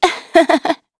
Xerah-Vox_Happy2.wav